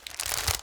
terumet_eat_vacfood.ogg